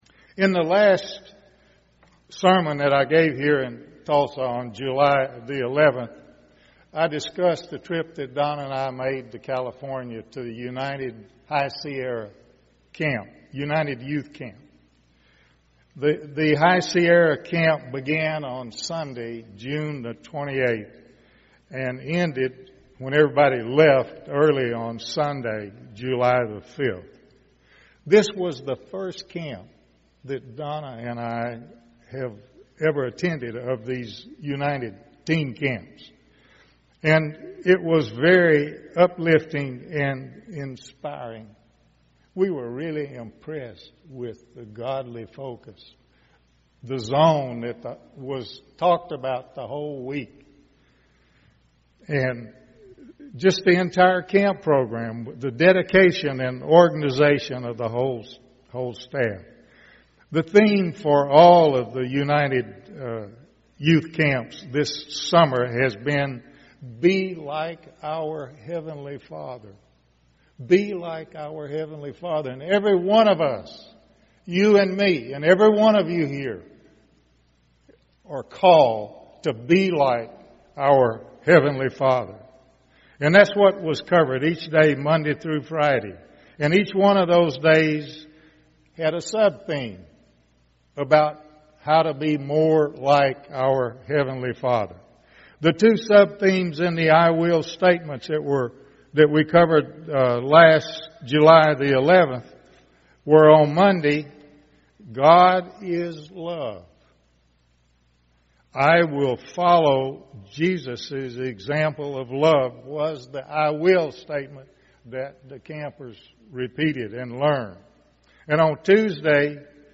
Given in Tulsa, OK
UCG Sermon Studying the bible?